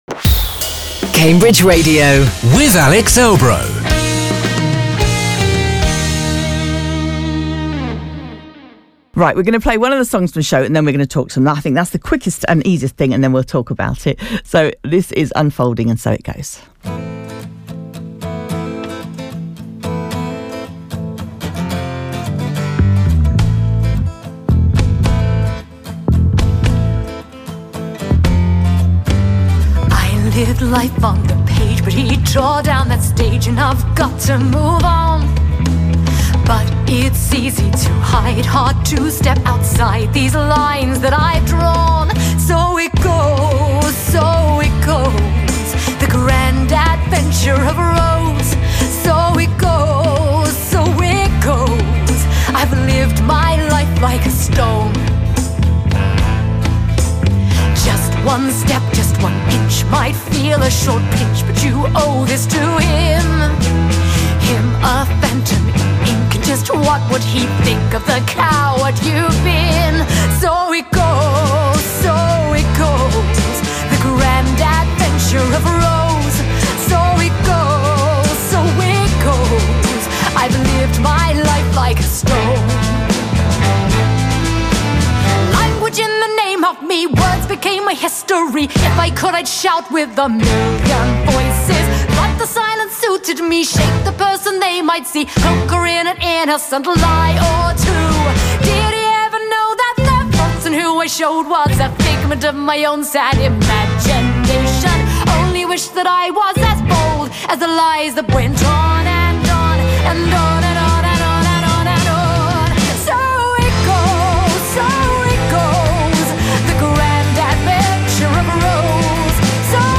in the studio to talk about it.